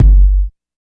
69 BOOM KICK.wav